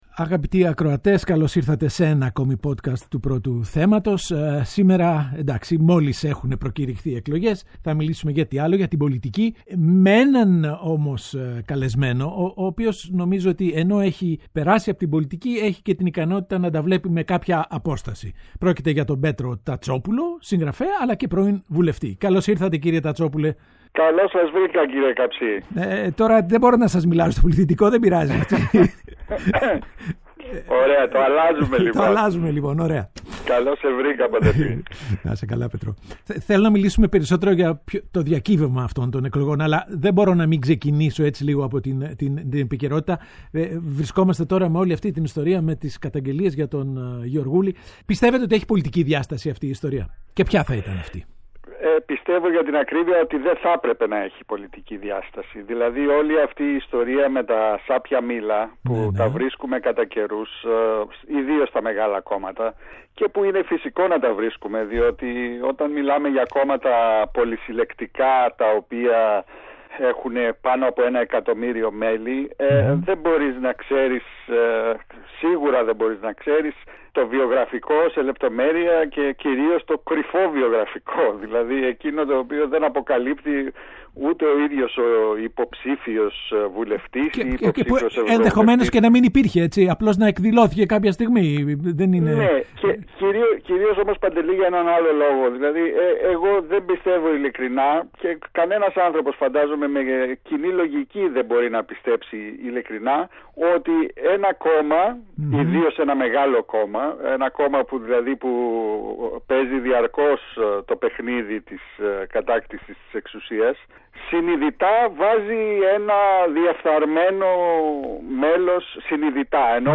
Ο Παντελής Καψής συζητά με τον Πέτρο Τατσόπουλο: Σάπια μήλα έχουν όλα τα κόμματα